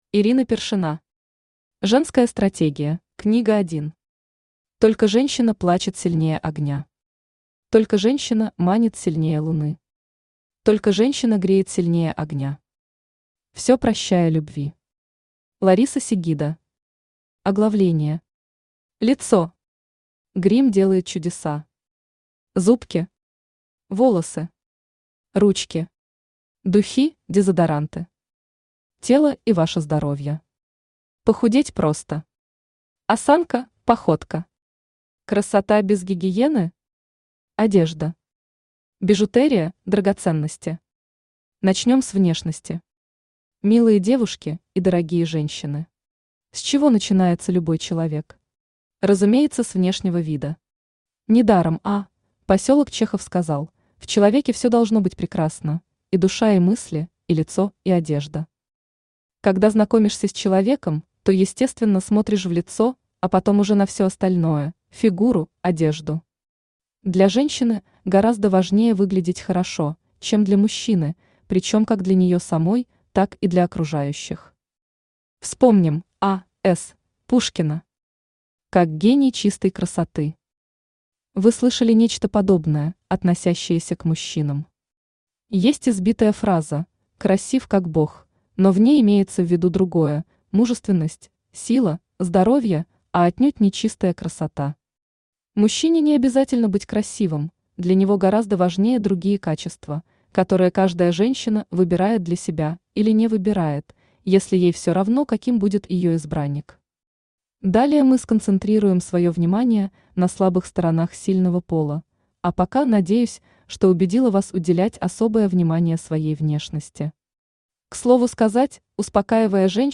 Аудиокнига Женская стратегия | Библиотека аудиокниг
Читает аудиокнигу Авточтец ЛитРес.